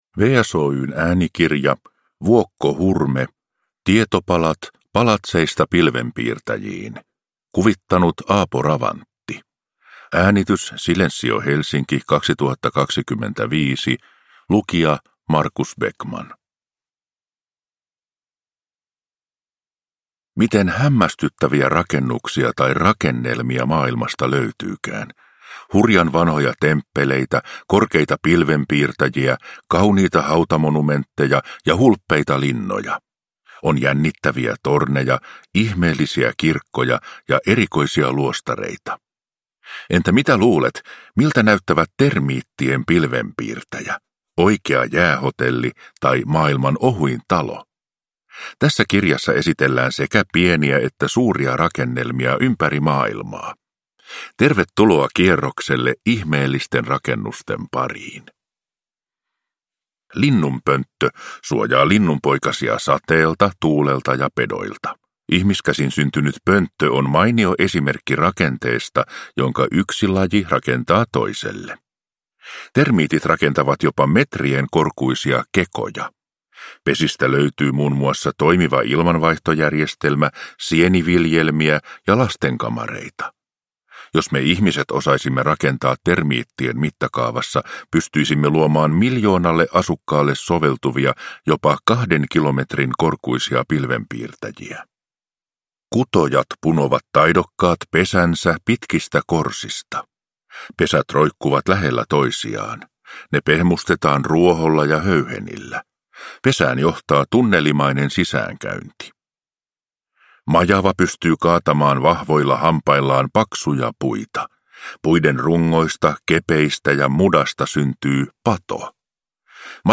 Tietopalat: Palatseista pilvenpiirtäjiin – Ljudbok